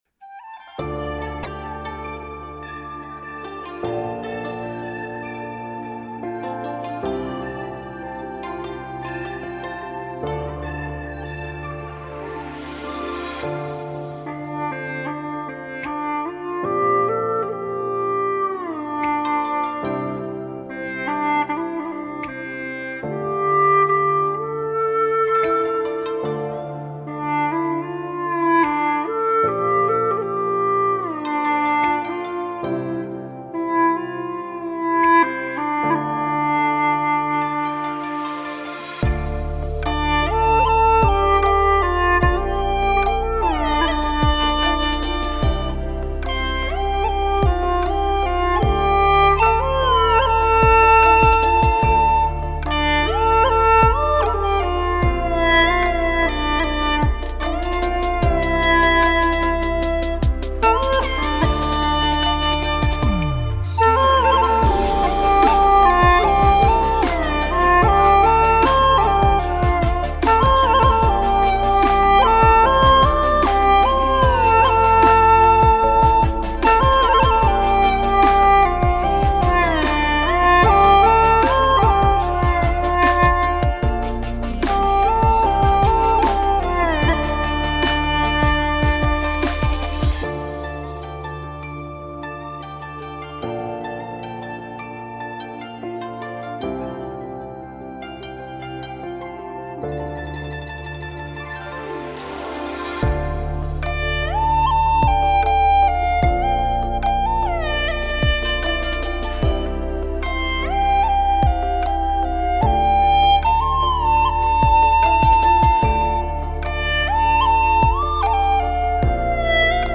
调式 : F 曲类 : 古风